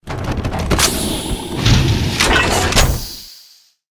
plasmacan.ogg